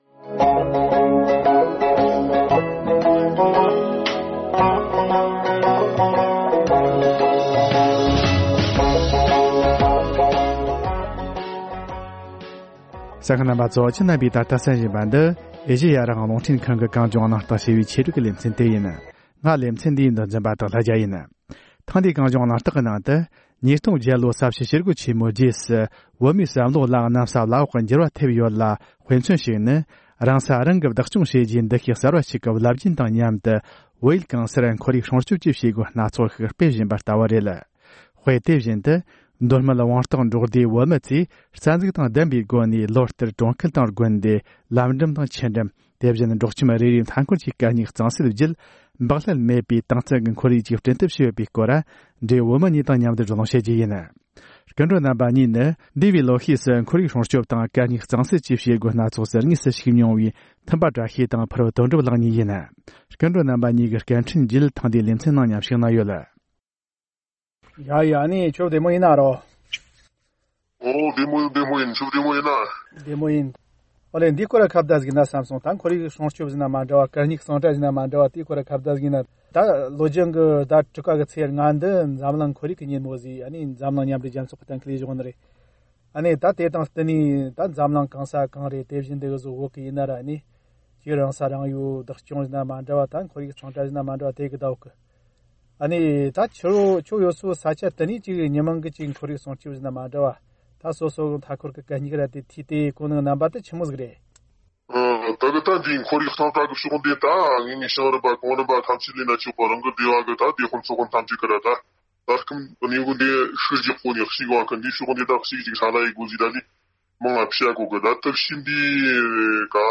བགྲོ་གླེང་གནང་བར་གསན་རོགས་ཞུ